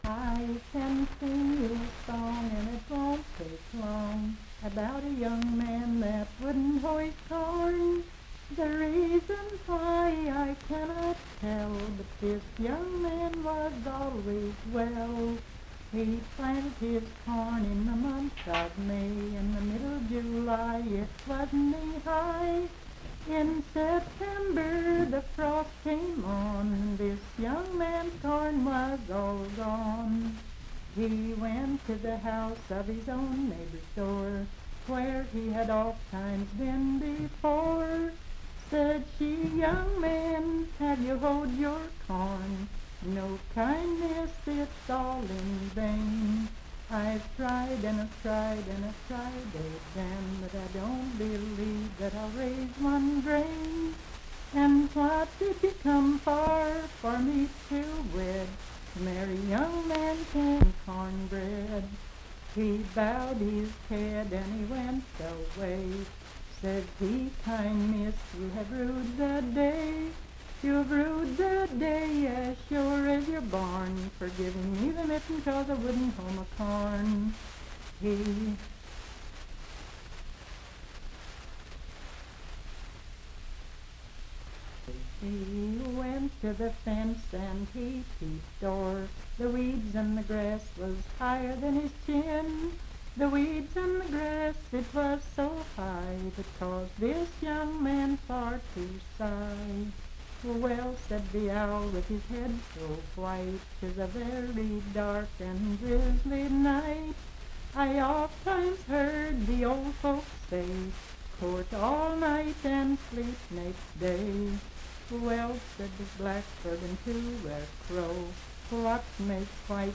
Unaccompanied vocal music
Verse-refrain 10(4).
Voice (sung)
Mineral County (W. Va.)